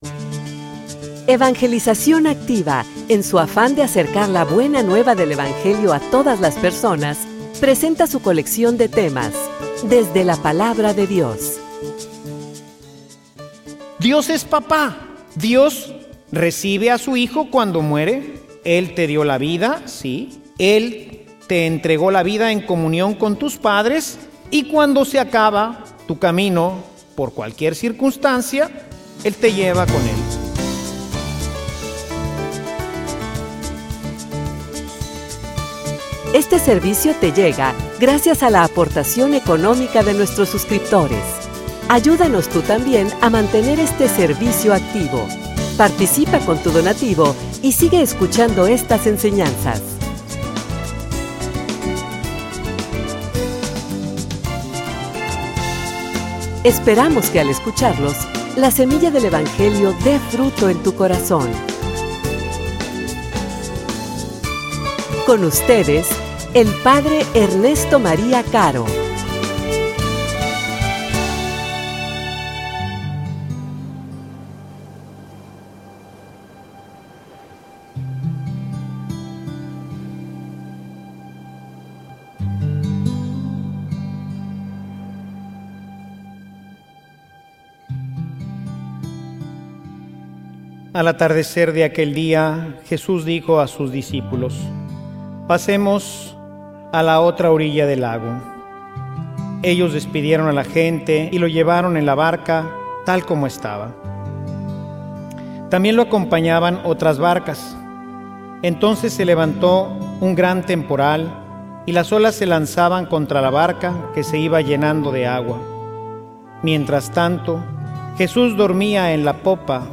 homilia_Dedica_tiempo_a_tu_familia.mp3